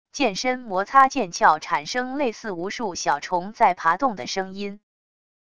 剑身摩擦剑鞘产生类似无数小虫在爬动的声音wav音频